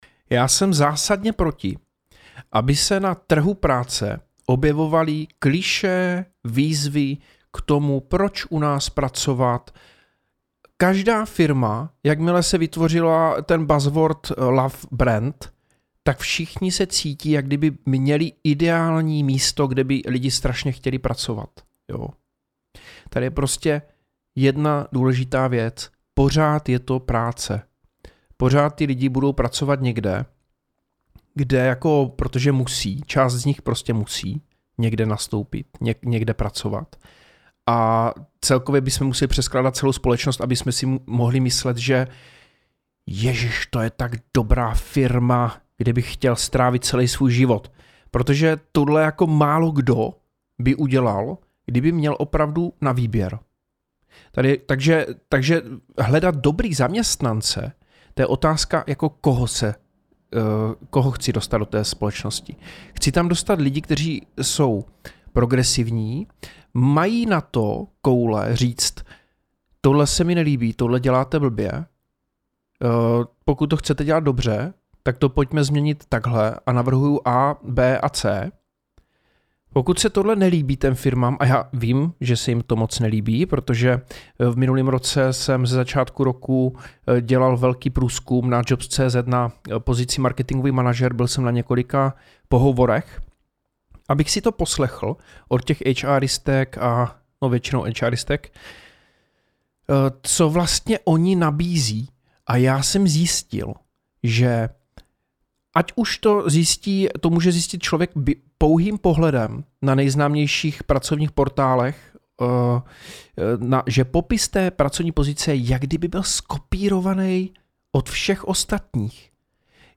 Tajemství úspěšného náboru a pracovní kultury (sestřih mých odpovědí z rozhovoru na rádiu Impuls)